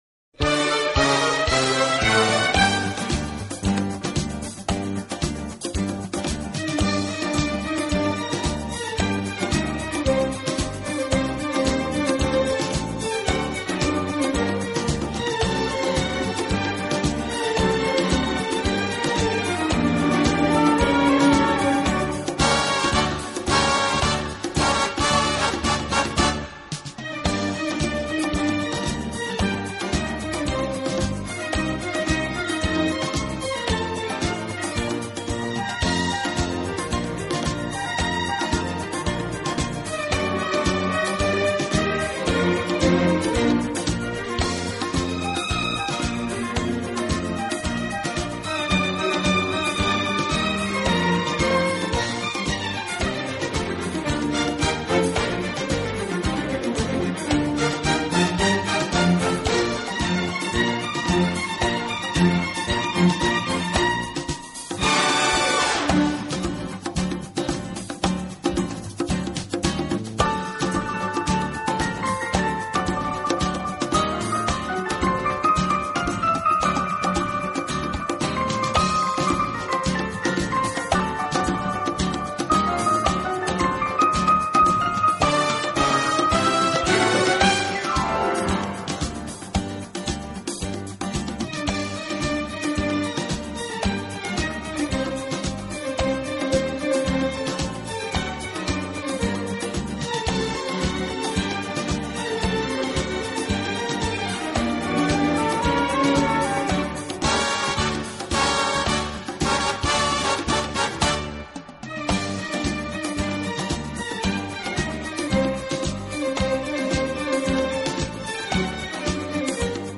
【轻音乐】
的轻音乐团，以萨克斯管为主，曲目多为欢快的舞曲及流行歌曲改编曲。
轻快、柔和、优美，带有浓郁的爵士风味。
Samba T/M 56